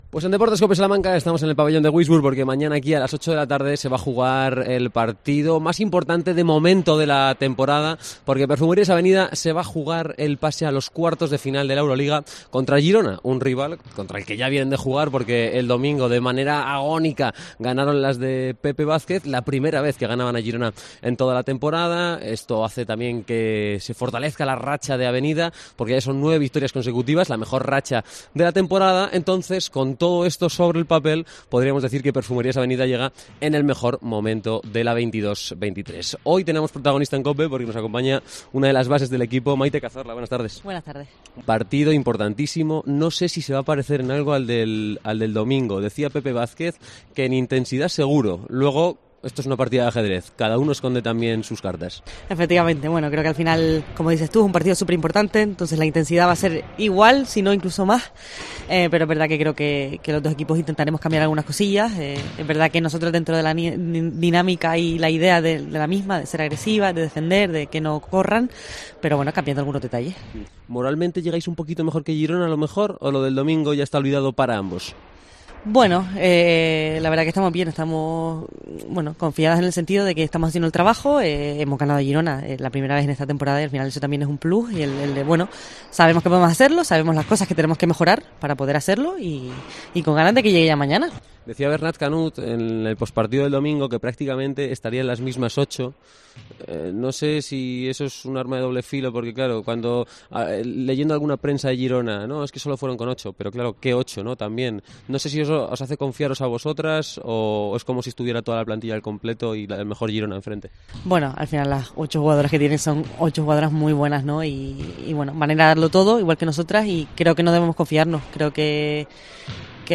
Entrevista con la base de Perfumerías Avenida, Maite Cazorla, a un día del partido contra Girona que decidirá la clasificación a los cuartos de final de la Euroliga